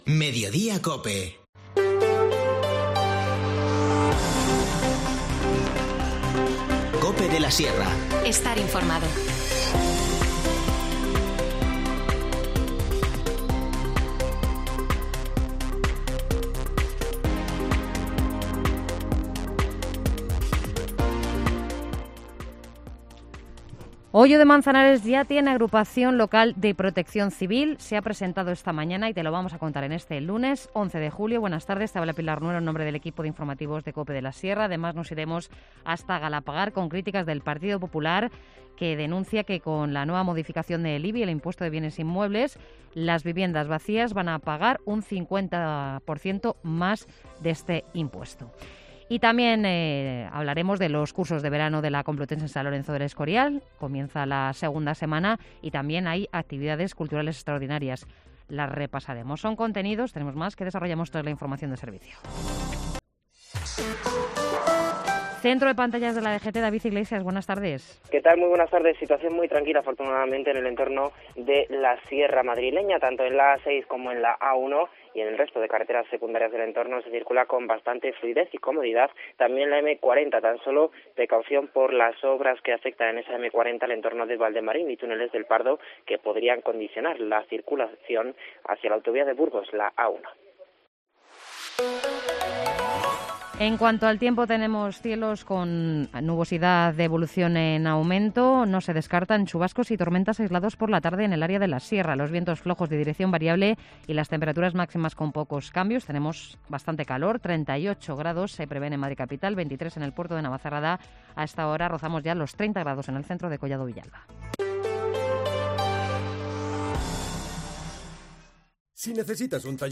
Informativo Mediodía 11 julio